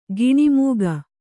♪ giṇi mūga